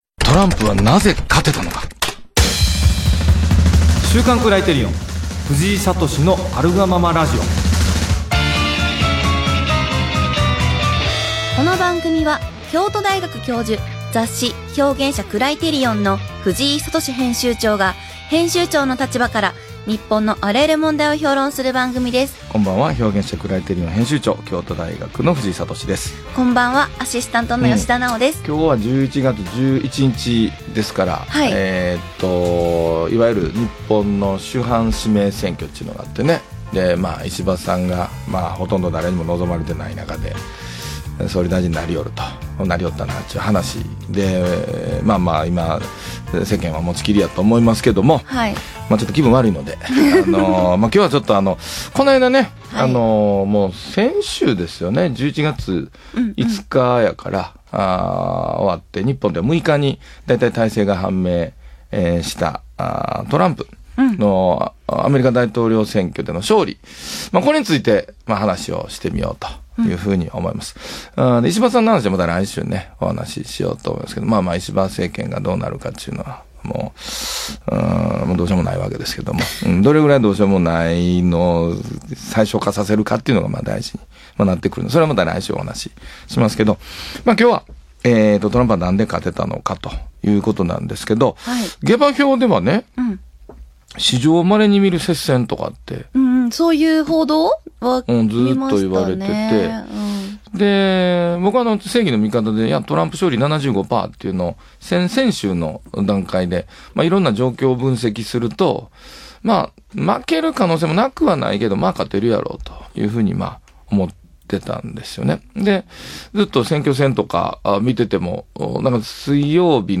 【ラジオ】トランプはなぜ，勝てたのか？